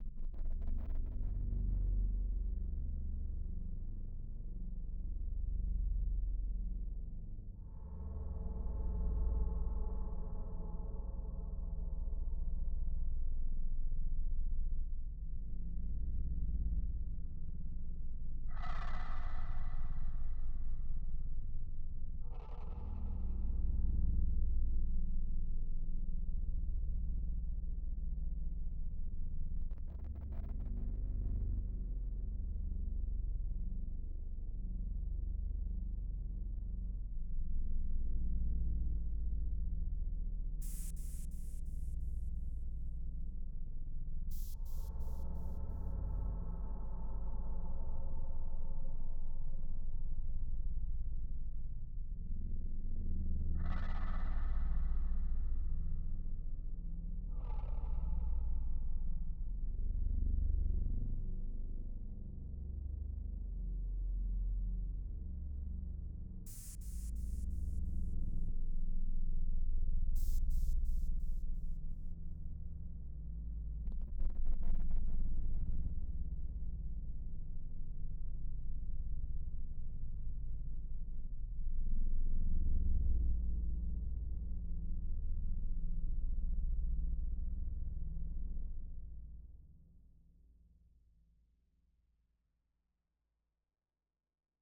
Ambient_01.wav